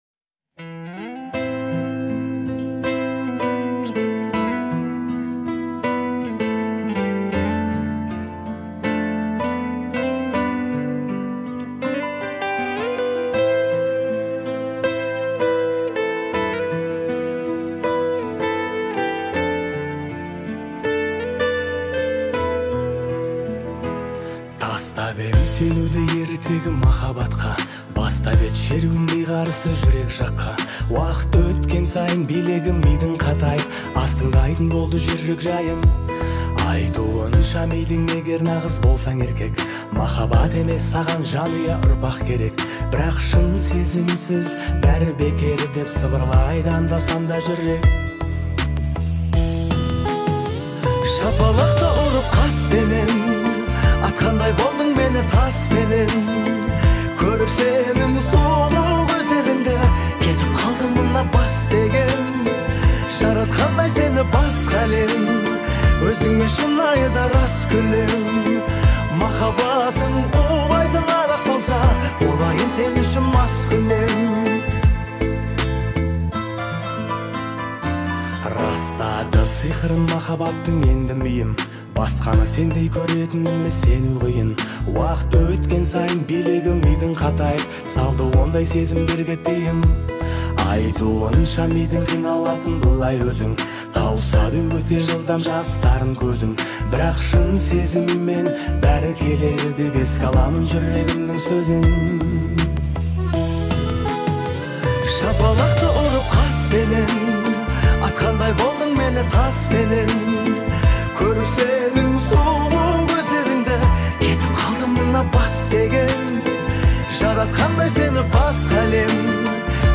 современными аранжировками и харизматичным исполнением